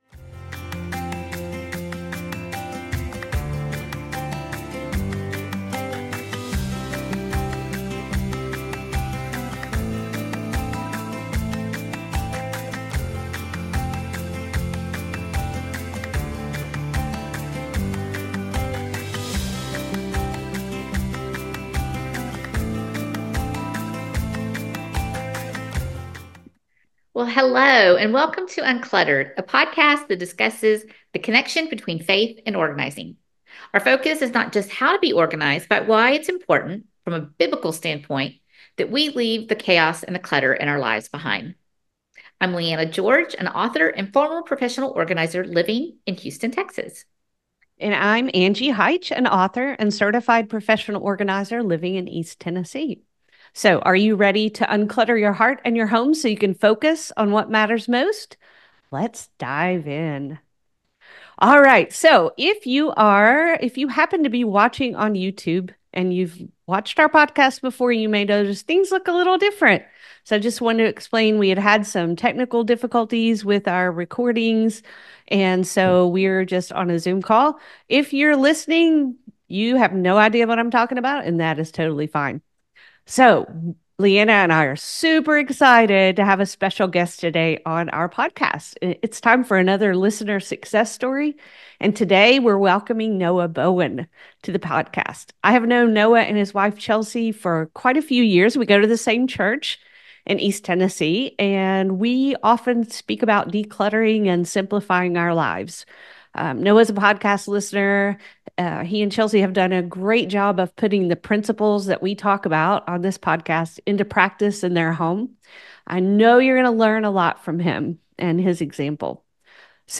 1 Listener Success Stories: An Interview